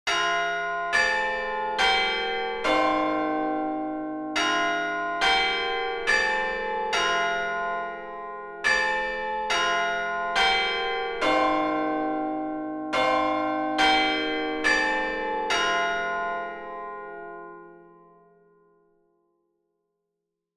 bell_ring.wav